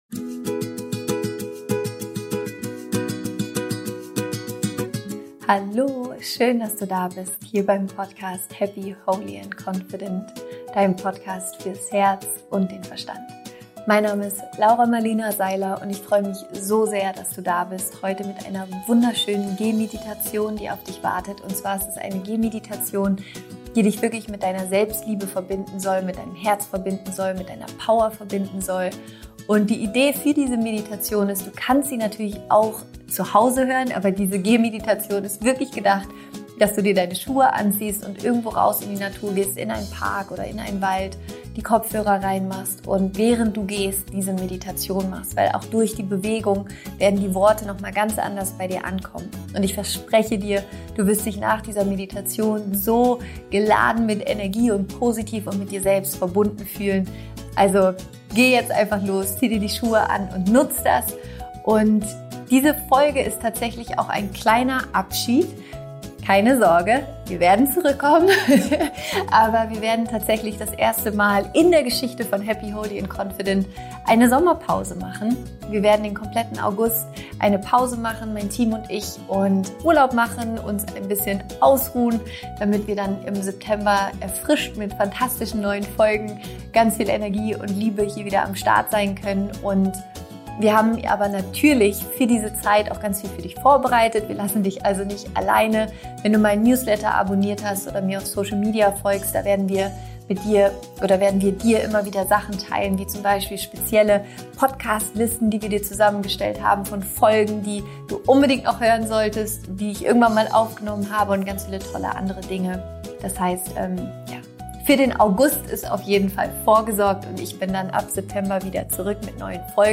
Dieses Mal gibt es eine wunderschöne Gehmeditation! Diese Gehmeditation verbindet dich mit deiner Selbstliebe, deinem Herzen und deiner absoluten Power!